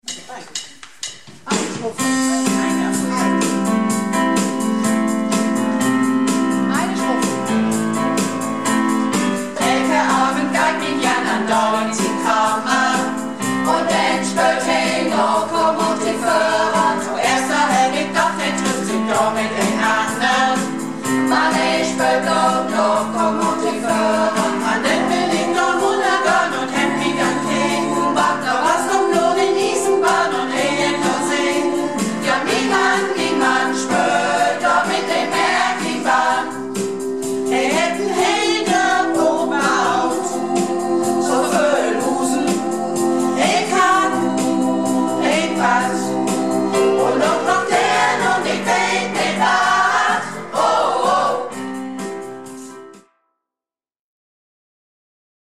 Malle Diven - Probe am 04.02.16